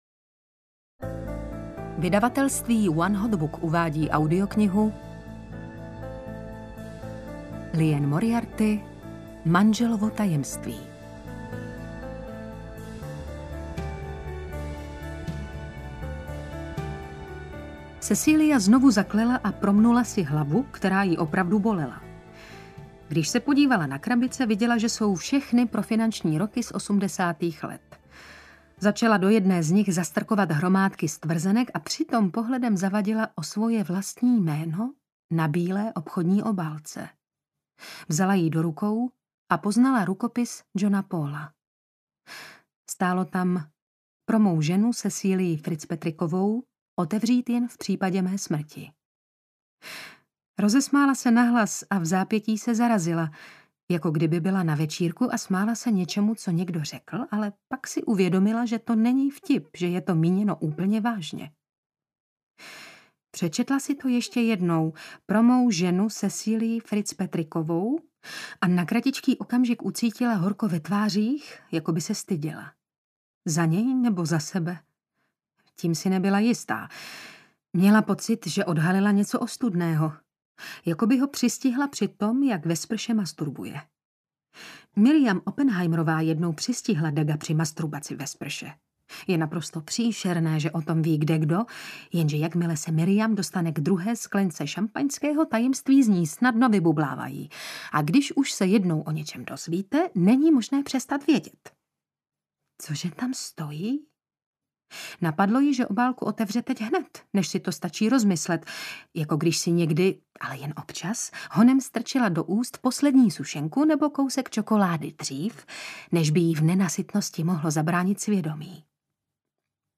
Manželovo tajemství audiokniha
Ukázka z knihy